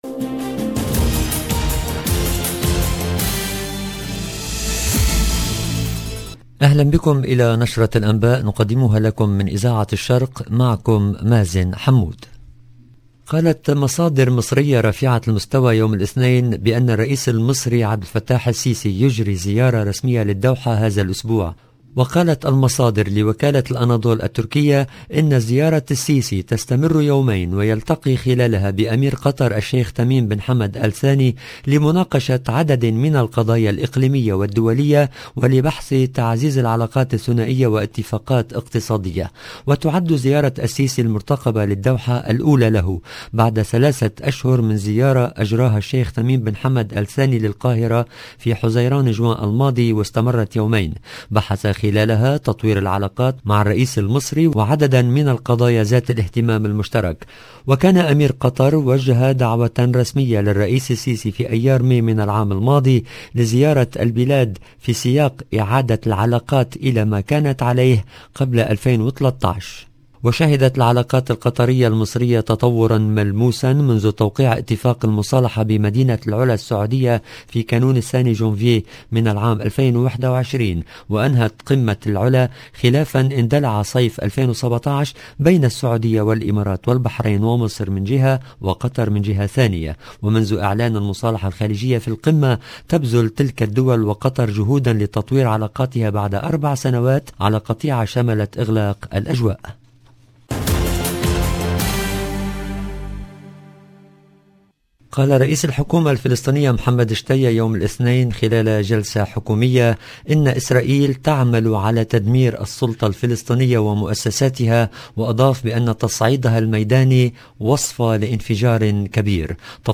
LE JOURNAL DU SOIR EN LANGUE ARABE DU 12/09/22